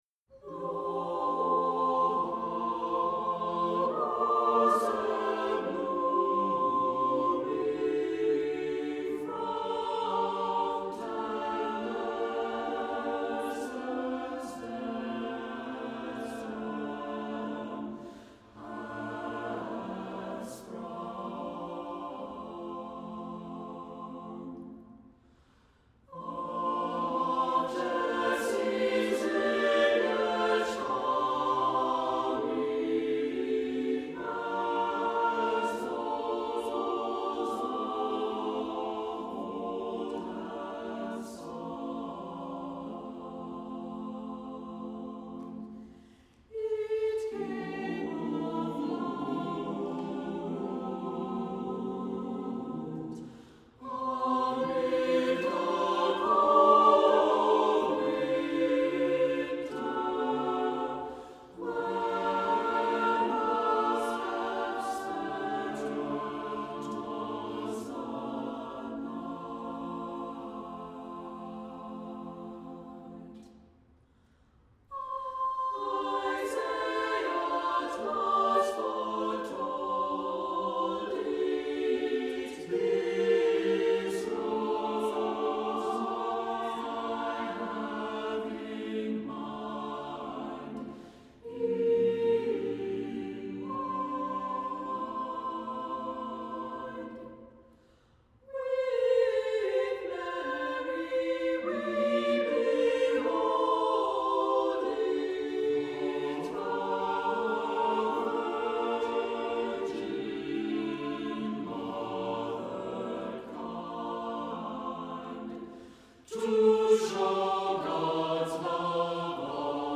Accompaniment:      Reduction
Music Category:      Choral
Best sung unaccompanied if your choir has good intonation.